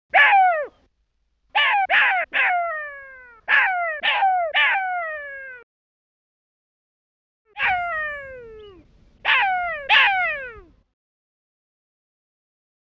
Jackal Barking Sound
animal
Jackal Barking